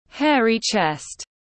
Lông ngực tiếng anh gọi là hairy chest, phiên âm tiếng anh đọc là /ˈheə.ri tʃest/.
Hairy chest /ˈheə.ri tʃest/